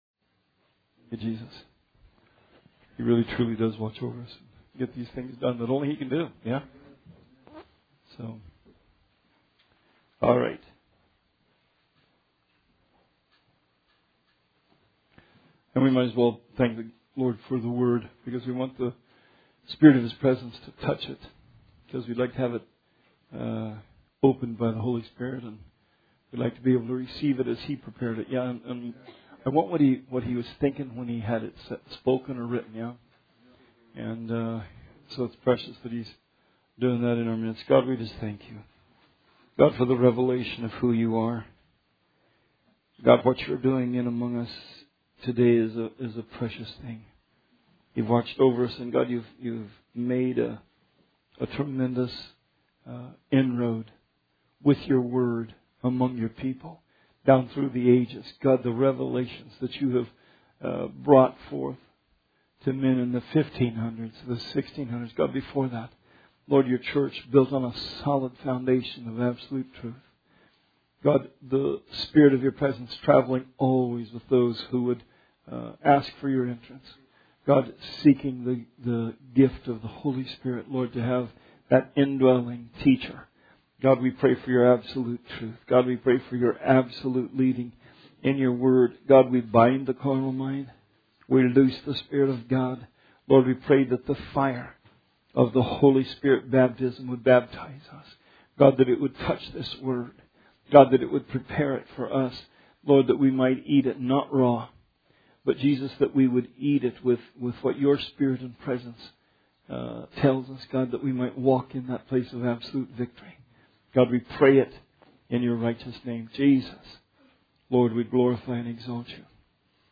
Bible Study 2/12/20